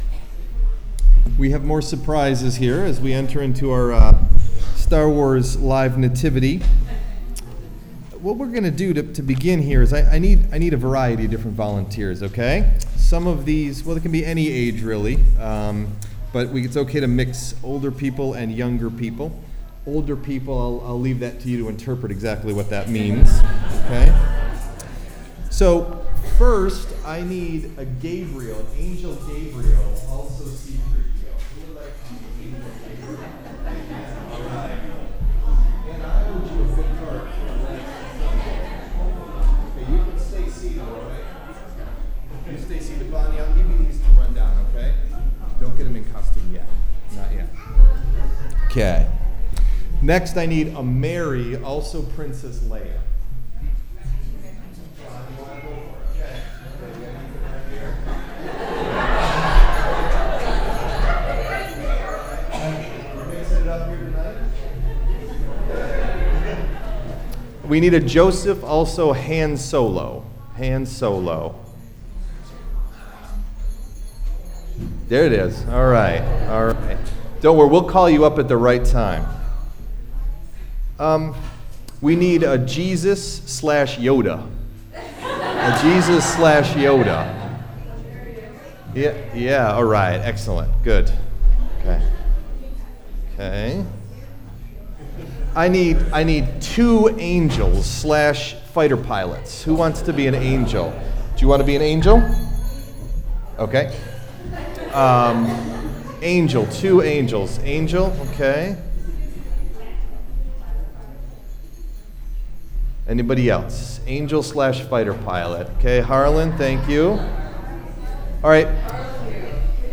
Date: December 24th, 2015 (Christmas Eve – Family Service)
Message Delivered at: The United Church of Underhill (UCC and UMC)
Here is an audio recording of our Star Wars themed “Live Nativity.” As the Christmas story is read, the “characters” come forward, get into costume, and assume a pose.